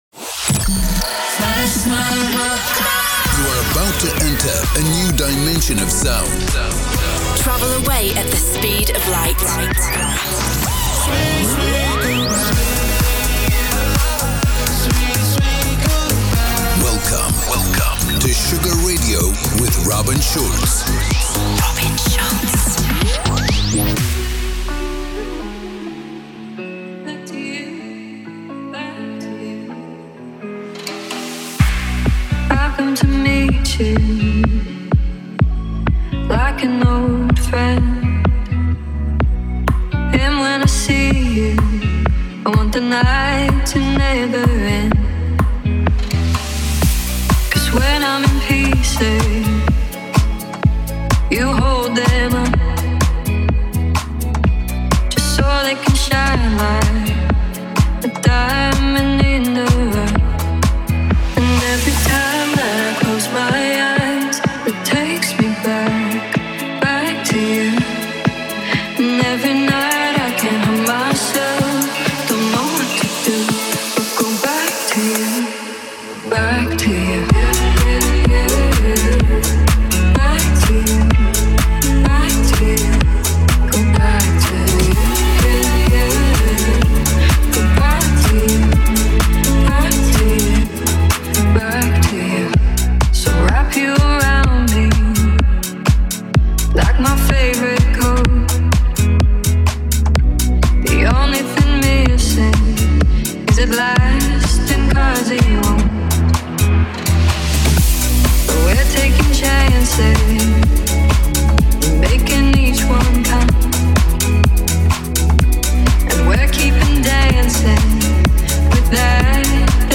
Genre: Electro Pop; Duration